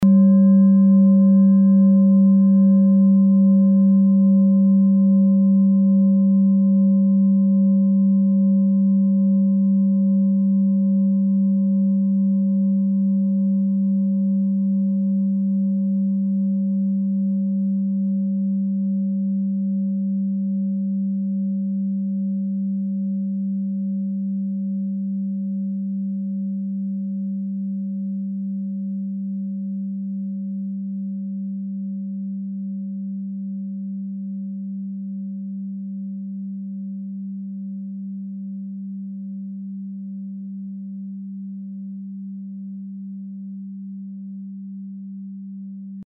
Klangschale TIBET Nr.19
Sie ist neu und ist gezielt nach altem 7-Metalle-Rezept in Handarbeit gezogen und gehämmert worden..
(Ermittelt mit dem Filzklöppel oder Gummikernschlegel)
Die 24. Oktave dieser Frequenz liegt bei 187,61 Hz. In unserer Tonleiter liegt dieser Ton nahe beim "Fis".
klangschale-tibet-19.mp3